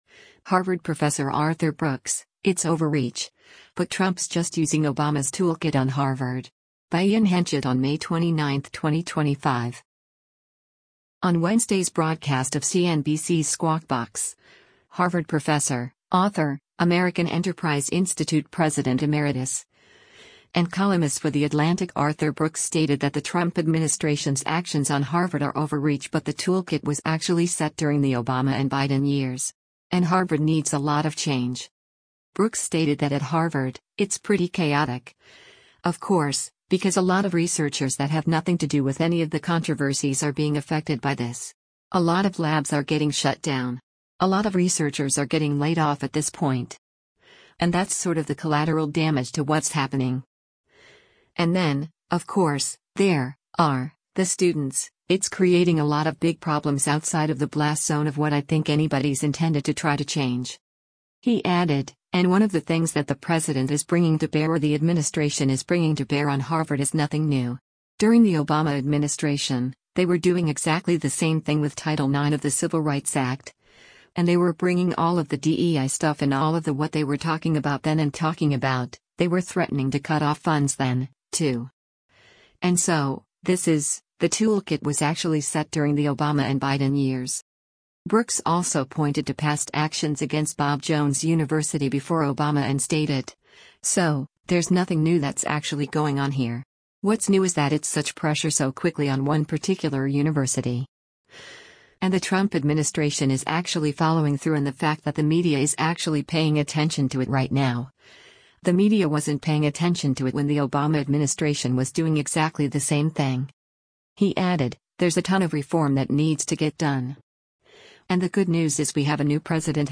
On Wednesday’s broadcast of CNBC’s “Squawk Box,” Harvard Professor, author, American Enterprise Institute President Emeritus, and columnist for The Atlantic Arthur Brooks stated that the Trump administration’s actions on Harvard are “overreach” but “the toolkit was actually set during the Obama and Biden years.”